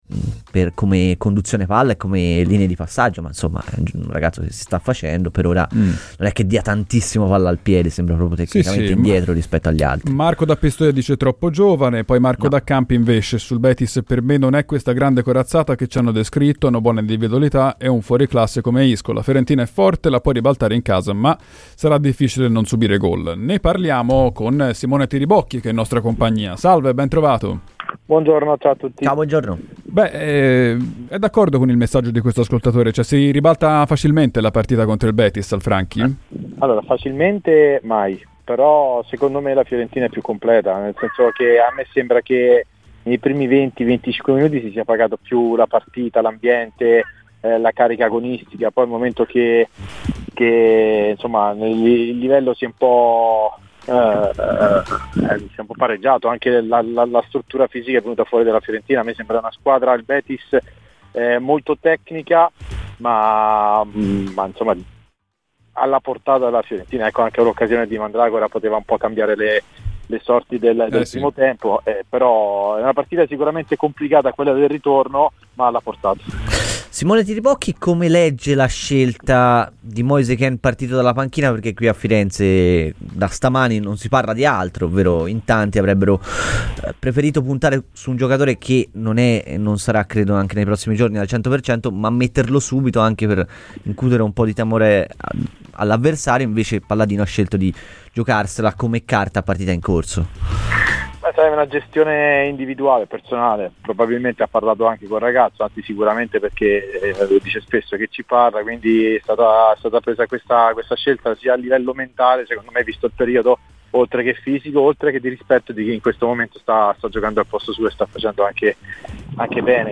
Simone Tiribocchi, ex attaccante di Atalanta e Torino tra le altre, è intervenuto a Radio FirenzeViola nel corso de "I tempi supplementari". Queste le sue dichiarazioni sulla Fiorentina, partendo dal ko di ieri in casa del Betis: